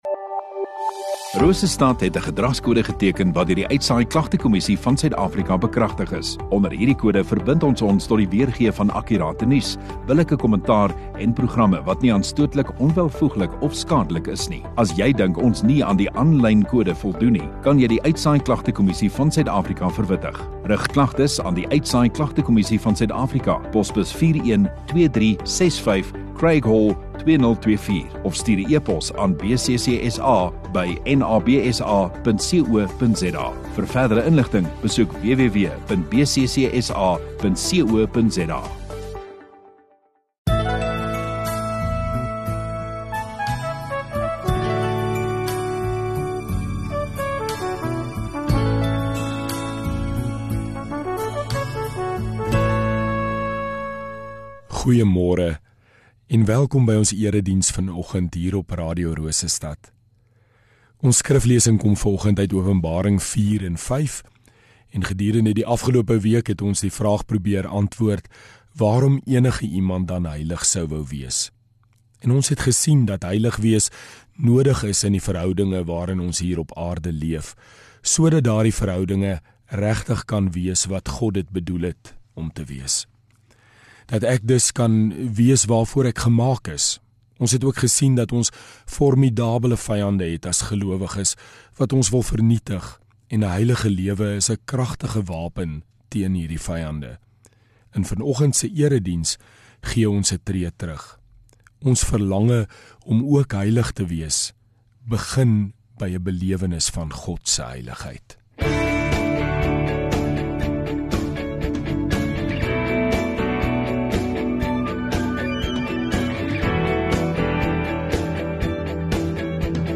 14 Jul Sondagoggend Erediens